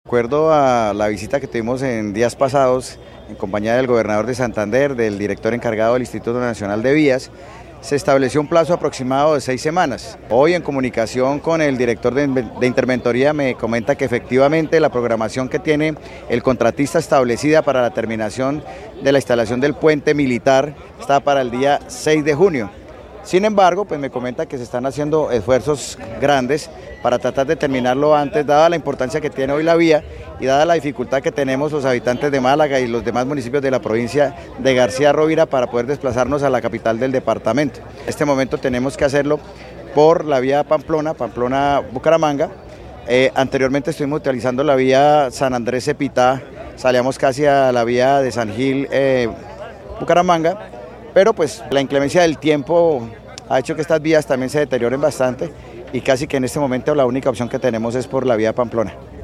Rubén Dario Moreno Méndez, alcalde de Málaga, Santander